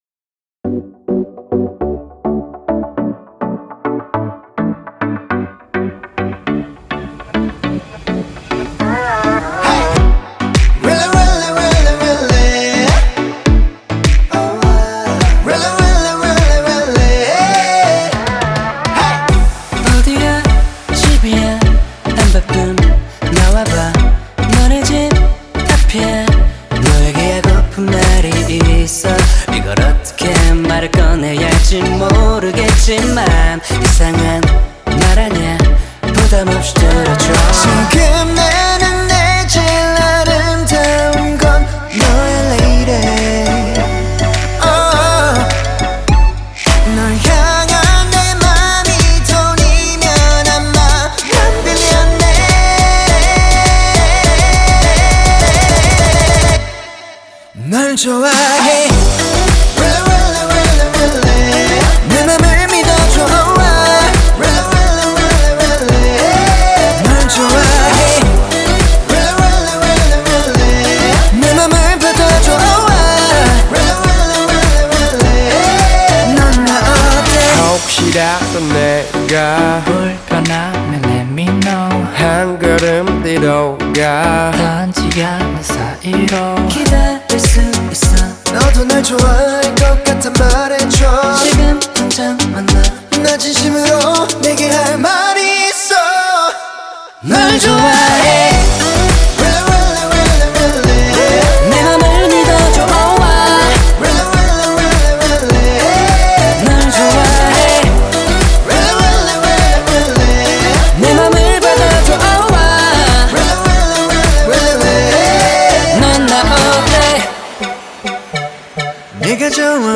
BPM103
MP3 QualityMusic Cut